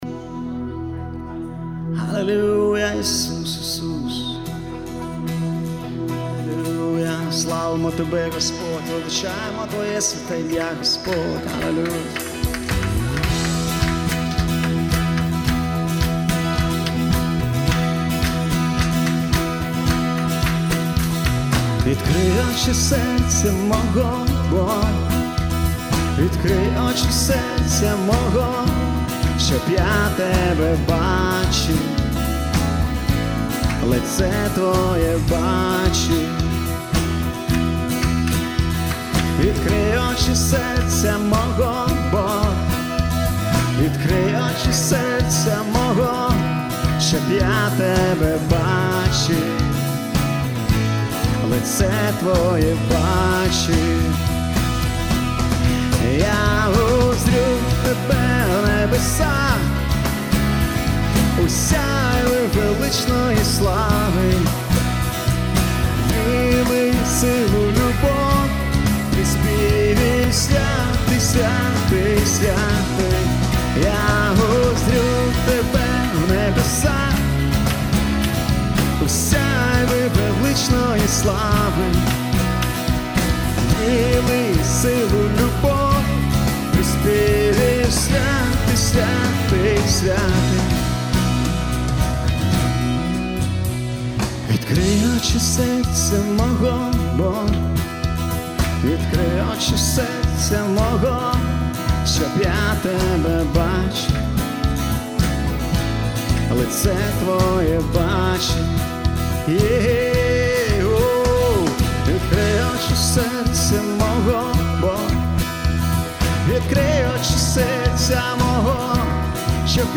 342 просмотра 99 прослушиваний 13 скачиваний BPM: 109